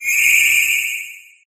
blipbug_ambient.ogg